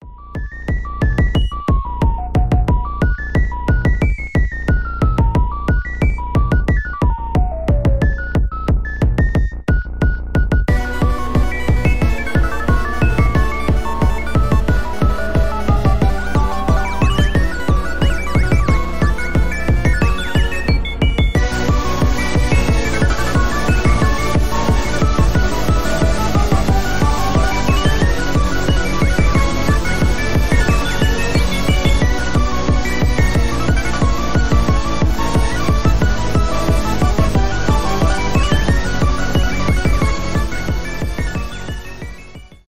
электронные
без слов